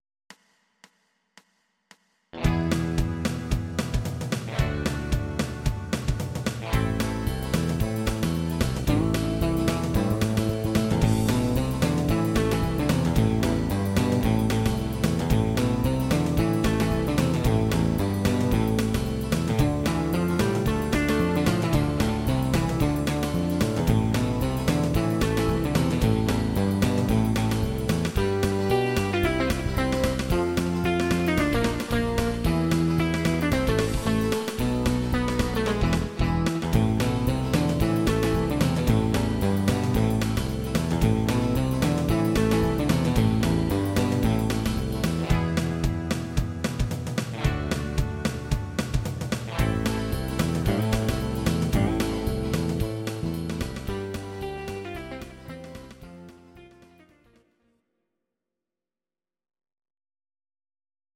These are MP3 versions of our MIDI file catalogue.
Please note: no vocals and no karaoke included.
instr. Gitarre